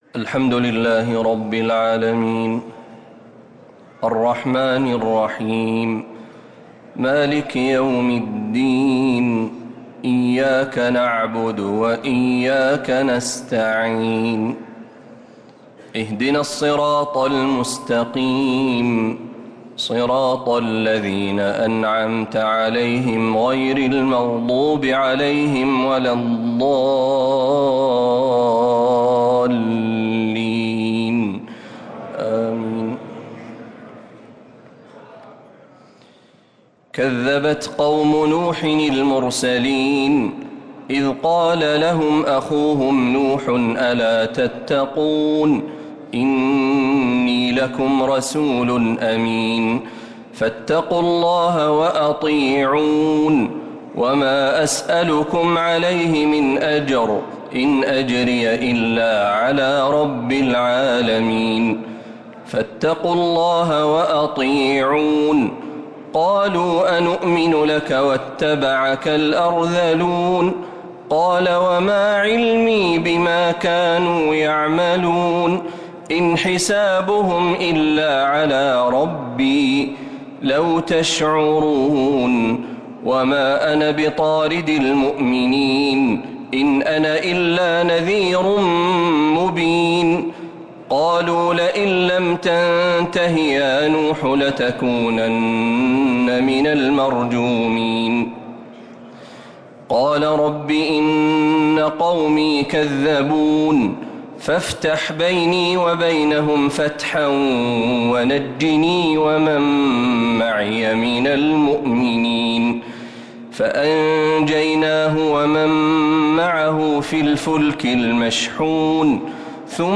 تراويح ليلة 23 رمضان 1446هـ من سورتي الشعراء {105-227} و النمل {1-44} taraweeh 23rd night Ramadan1446H surah Ash-Shuara and An-Naml > تراويح الحرم النبوي عام 1446 🕌 > التراويح - تلاوات الحرمين